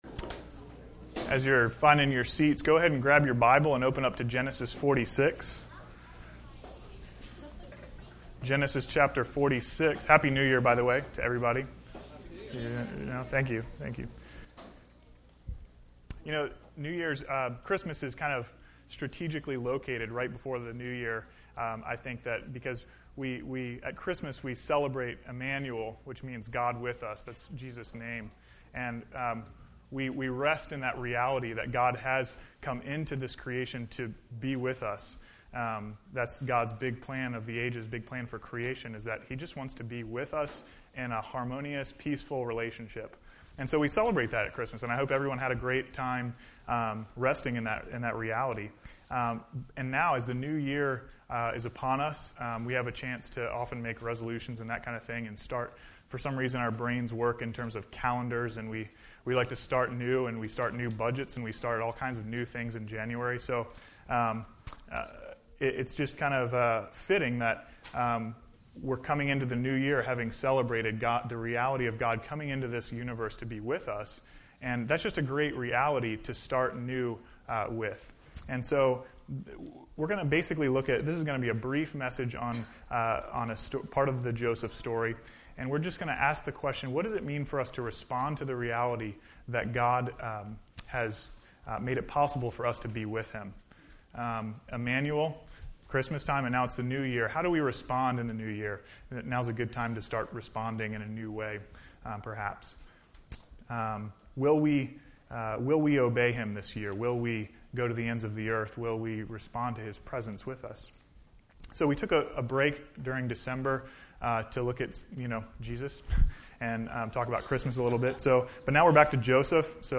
A message from the series "Heaven Help The Home."